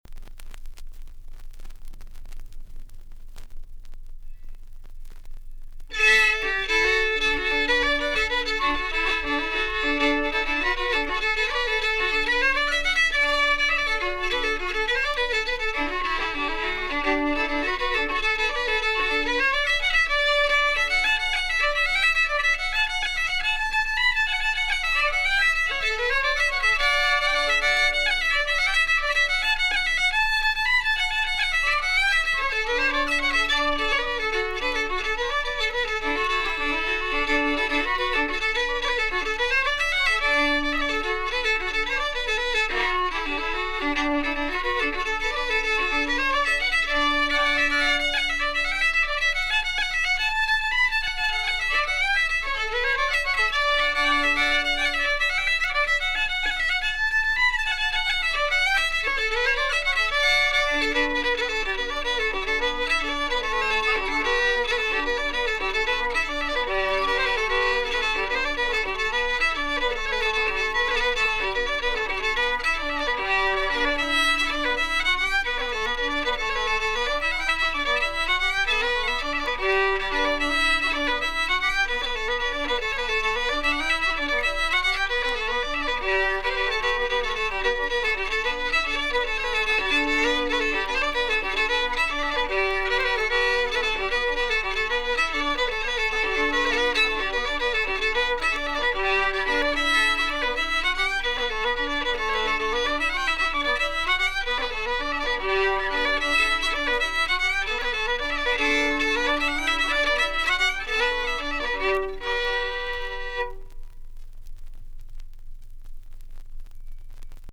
fiddle
Recorded in Dublin.